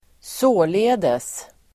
Uttal: [²s'å:le:des]